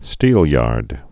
(stēlyärd)